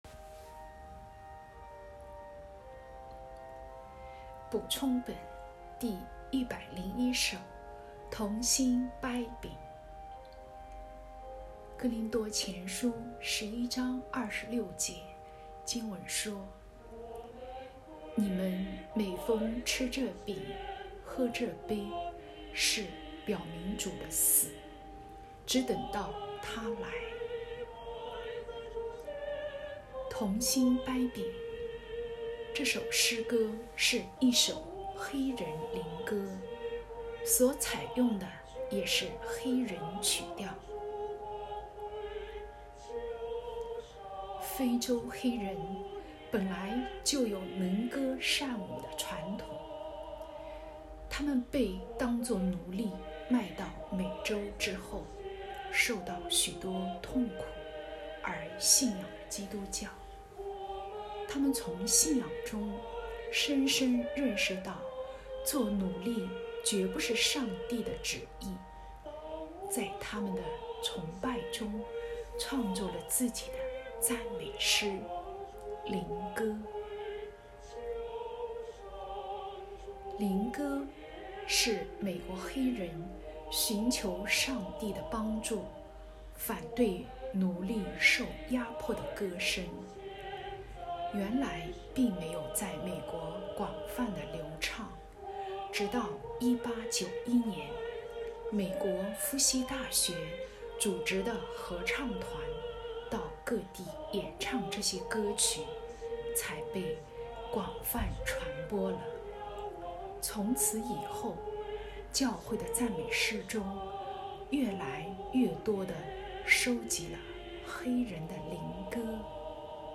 （林前11:26） 《同心擘饼》这首诗歌是一首黑人灵歌，所采用的也是黑人曲调。